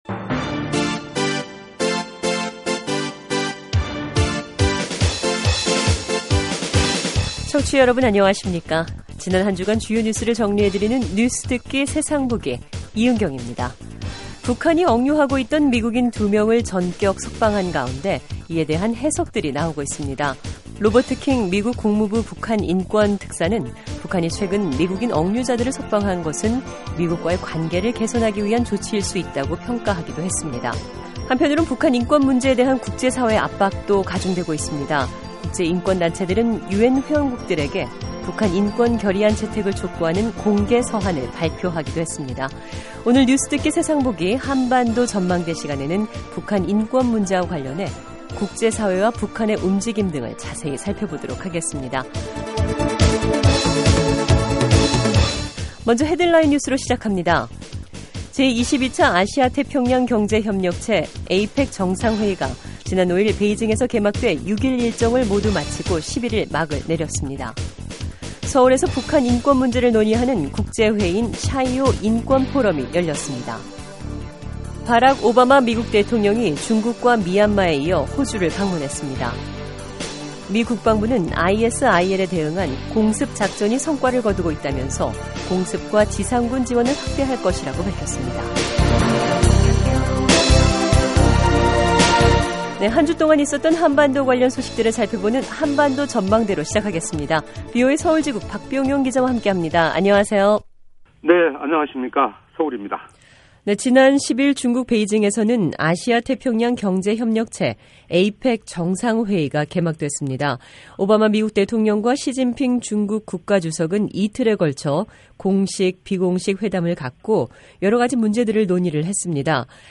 지난 한주간 주요 뉴스를 정리해 드리는 뉴스듣기 세상보기입니다. 유엔에서 북한인권 범죄 책임자를 국제형사재판소에 회부하는 결의안이 추진되고 있습니다. 바락 오바마 미국 대통령이 중국에 이어 미얀마와 호주를 방문했습니다. 미 국방부는 ISIL에 대응한 공습작전이 성과를 거두고 있다고 밝혔습니다.